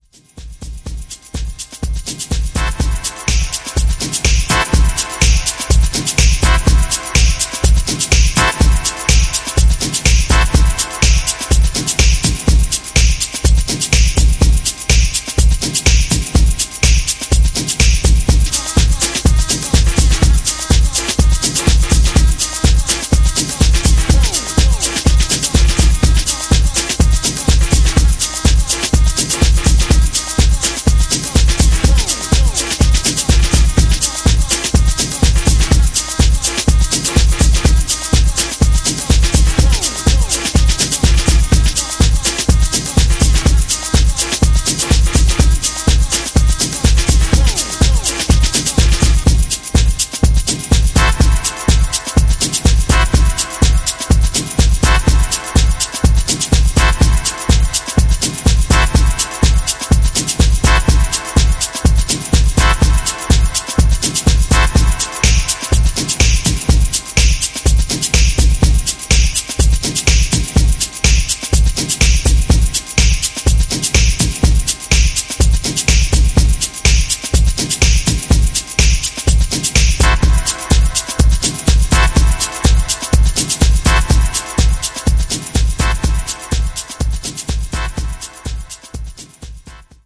ジャンル(スタイル) JAZZ / HOUSE / DOWNTEMPO / CLASSIC / DEEP HOUSE